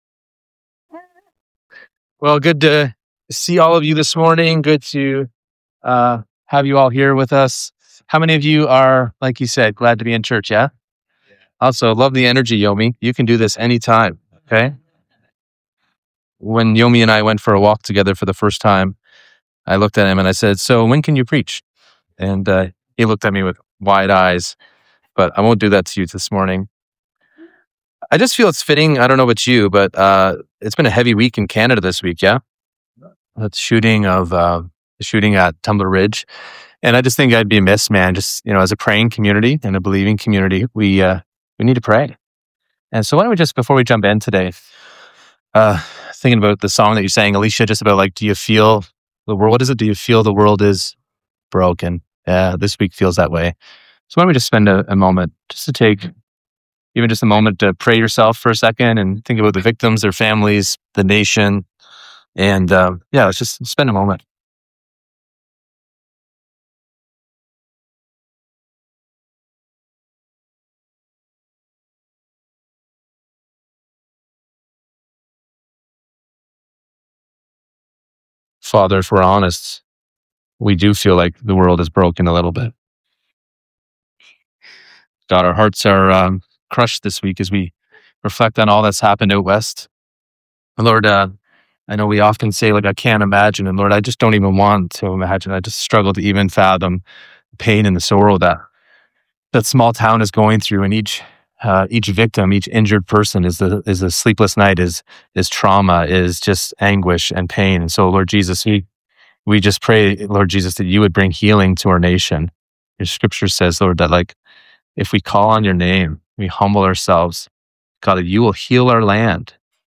This sermon reminds us that unlike Israel of old, believers today have new hearts through the Holy Spirit, empowering us to live as lights in a crooked generation. The call is clear: don’t run from the darkness — shine in it.